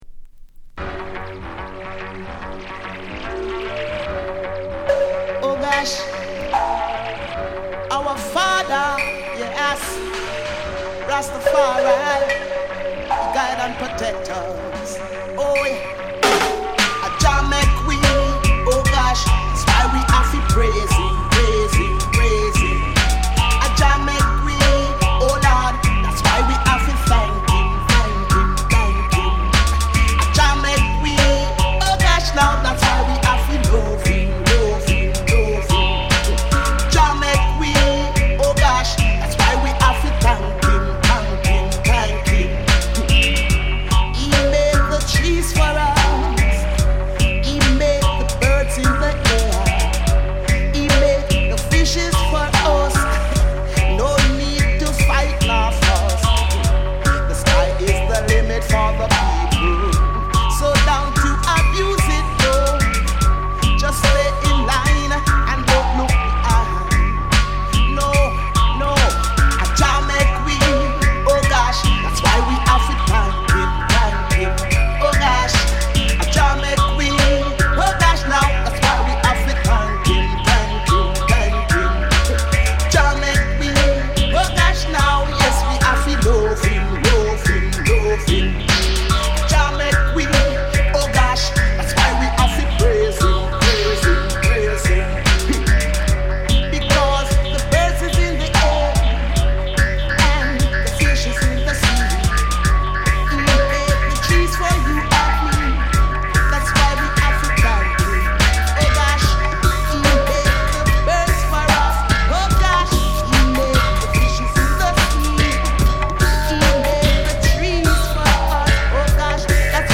Off the cuff. 56mins - 128kbit MP3 1.